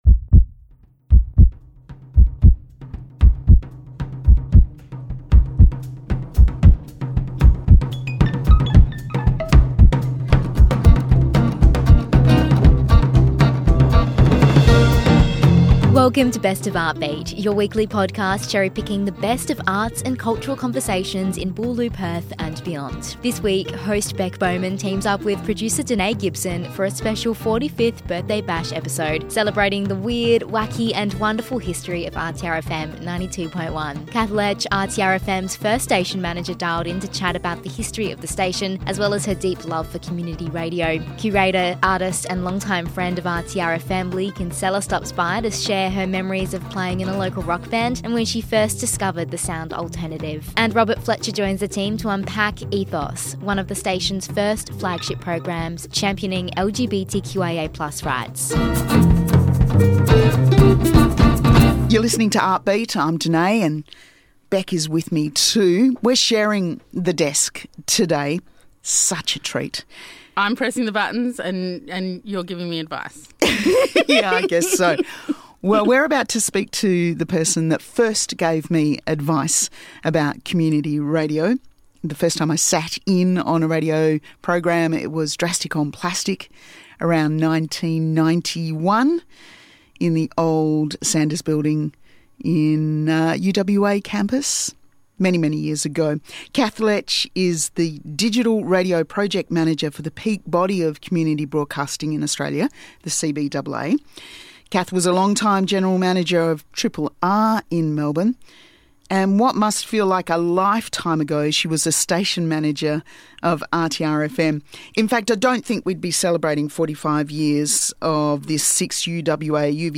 Welcome to Best of Artbeat, your weekly podcast cherry picking the best conversations in arts and culture in Boorloo and beyond.